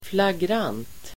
Uttal: [flagr'an:t]